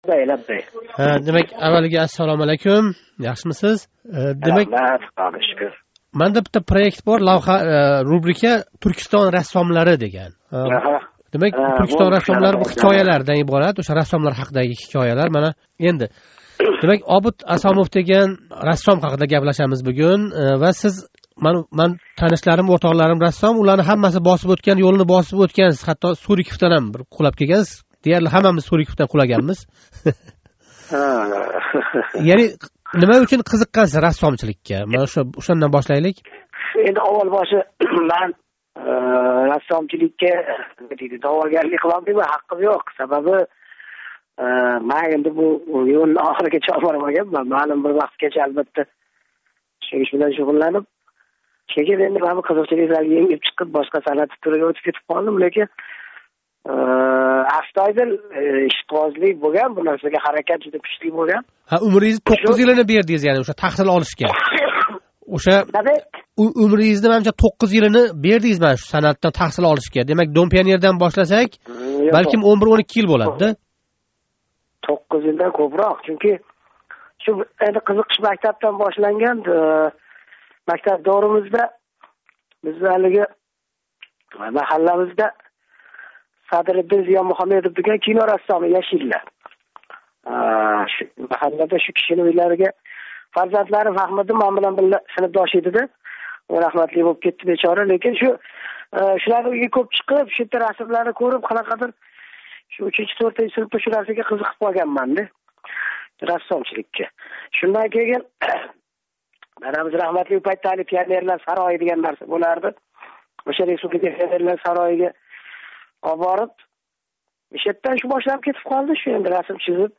Обид Асомов билан суҳбат